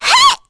Miruru-Vox_Attack4.wav